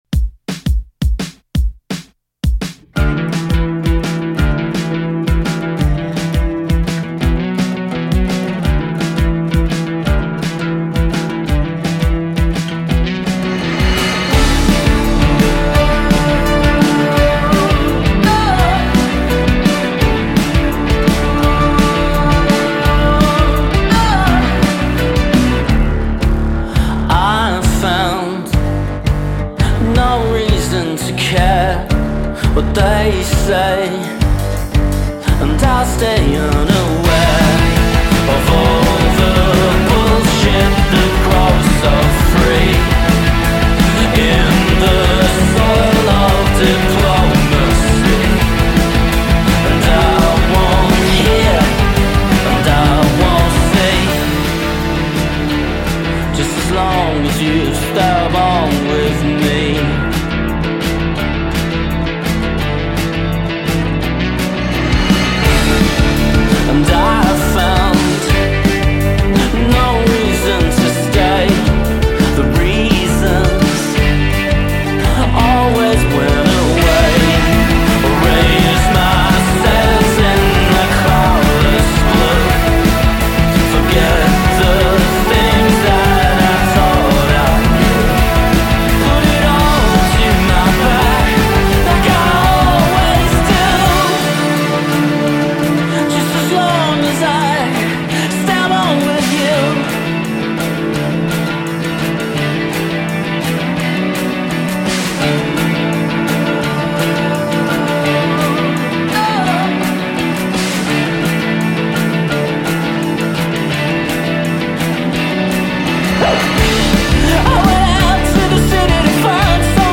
a four-piece indie rock group from Hobart Australia